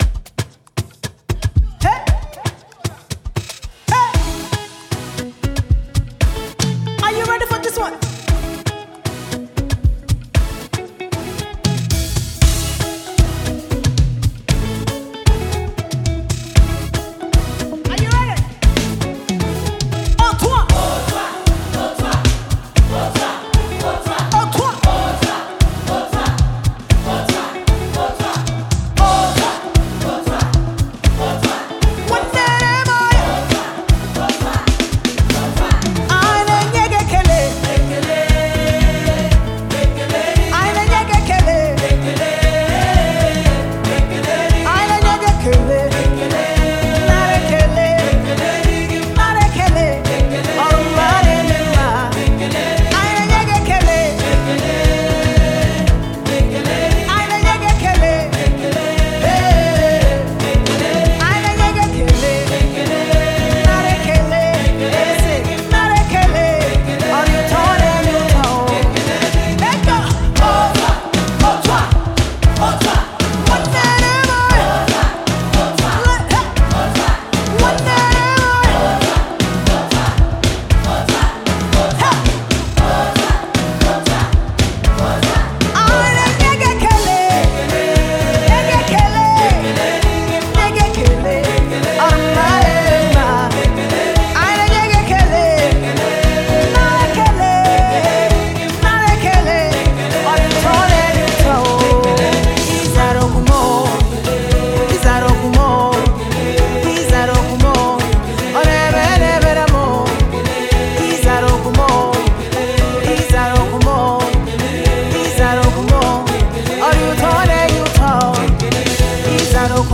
is a danceable gospel tune for all.